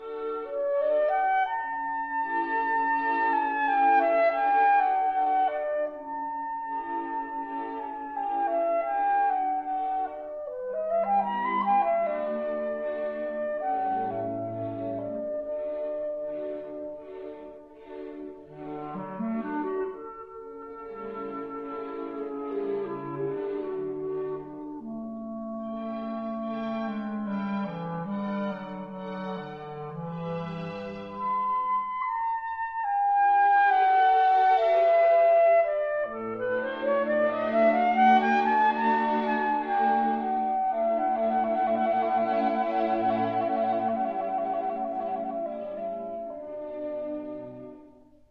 Recorded in July 1954